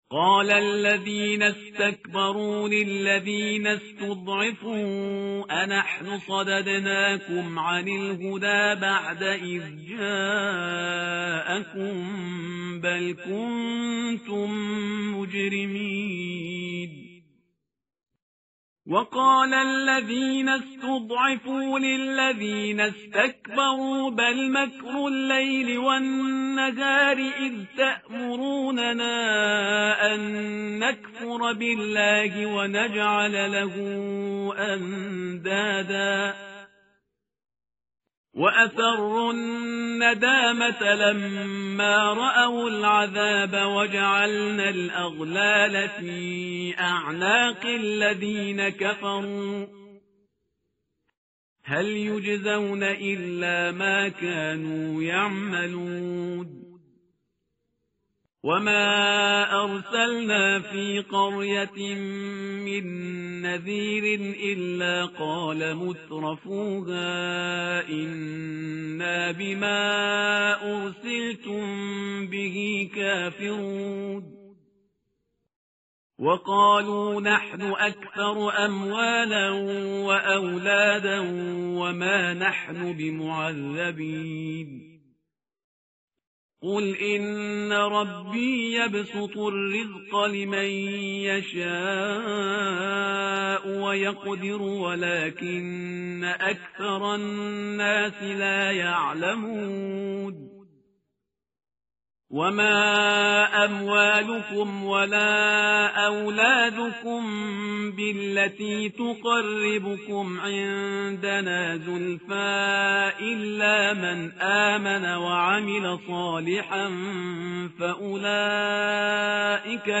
متن قرآن همراه باتلاوت قرآن و ترجمه
tartil_parhizgar_page_432.mp3